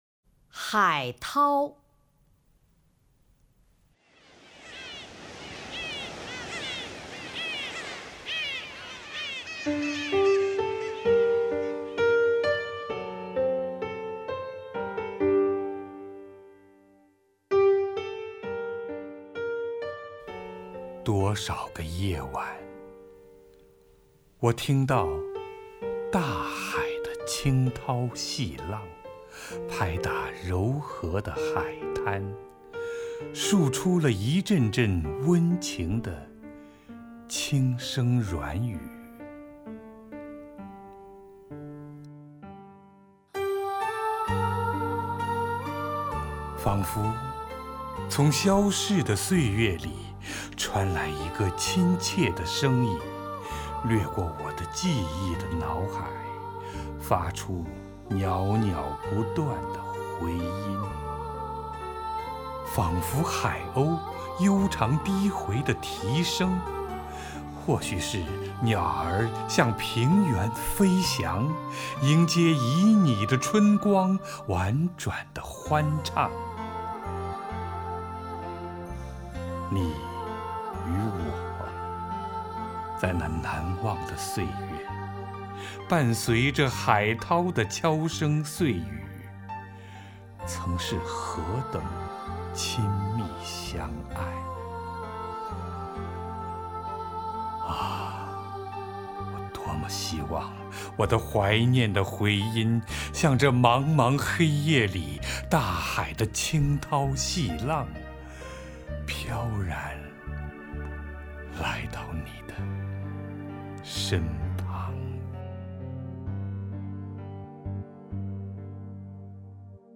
[22/9/2008]乔榛配乐诗朗诵 夸齐莫多《海涛》(320K MP3) 激动社区，陪你一起慢慢变老！